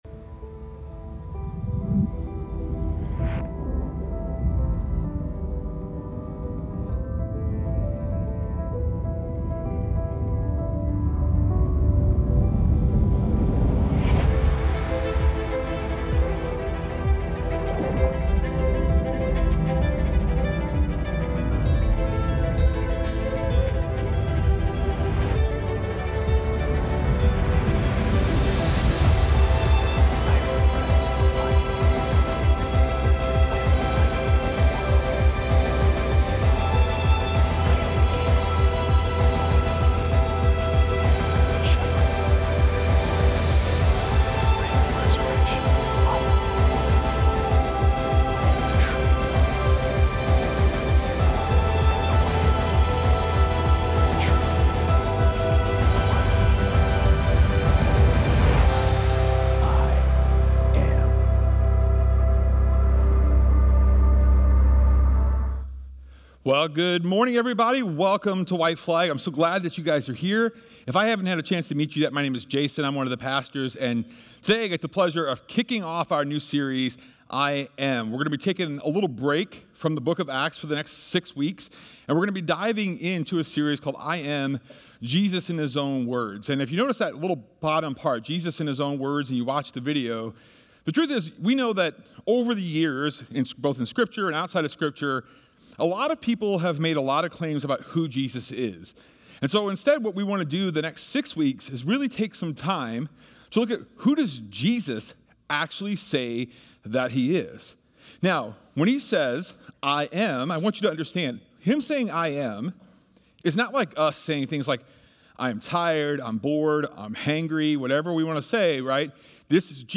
i-am-week-1-sermon-audio.mp3